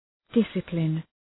Shkrimi fonetik {‘dısəplın}
discipline.mp3